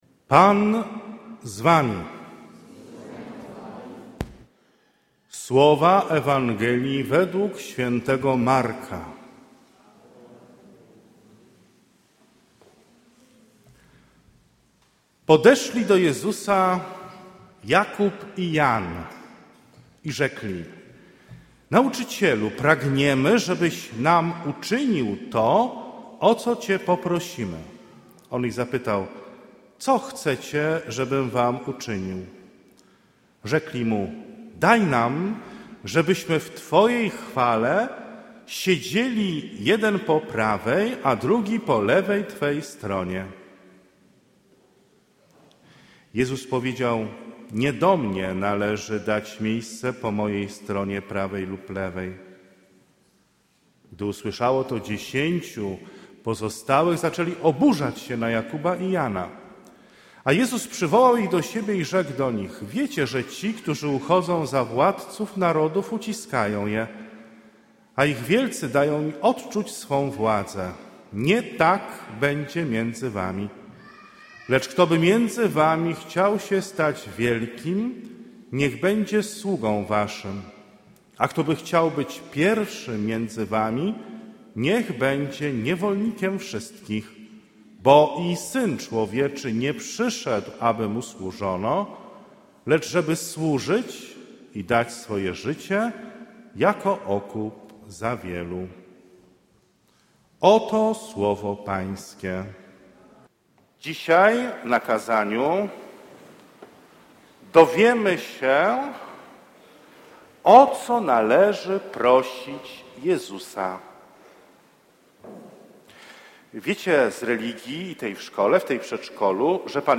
Kazanie dla dzieci – 29 niedziela zwykła B